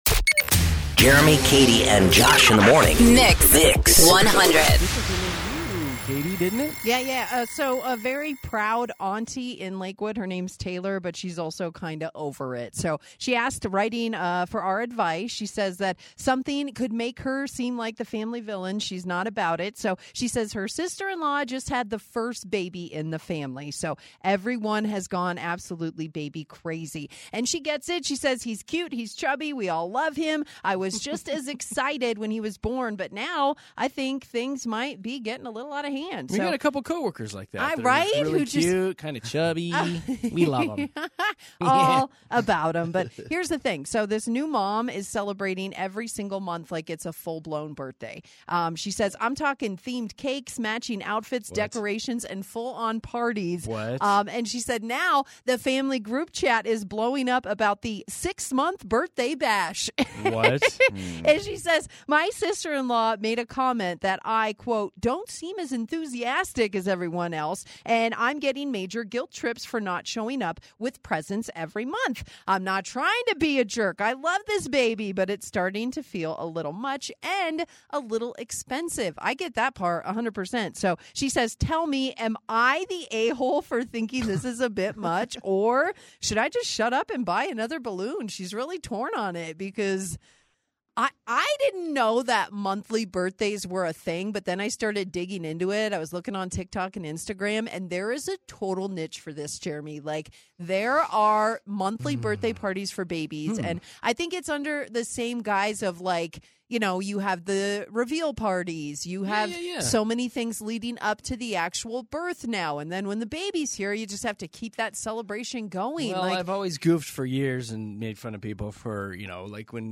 Denver's favorite morning radio show!